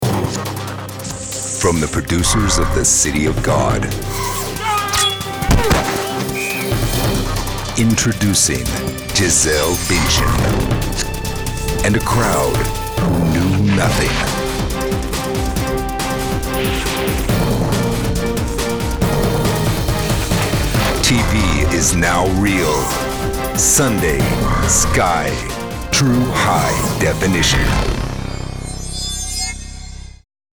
Friendly, honest, casual, American voice
Sprechprobe: Sonstiges (Muttersprache):